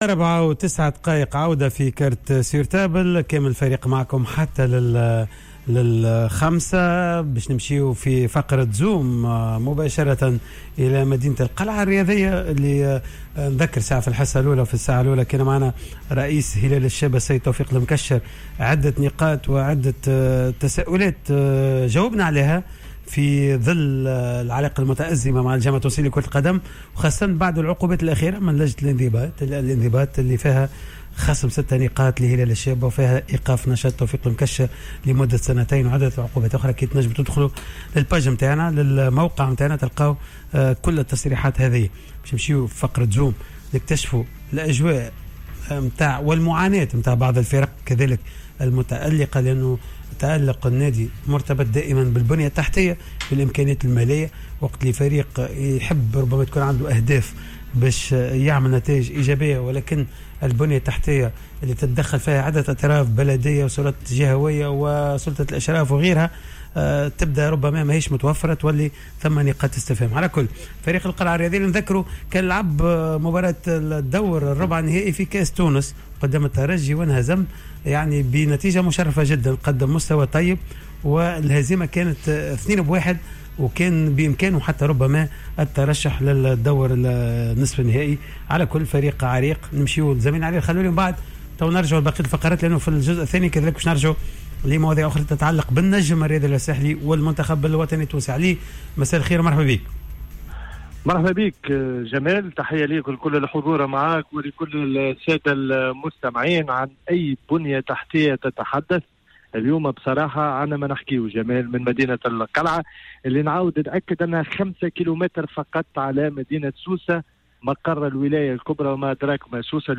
الجزء الاول من الحصة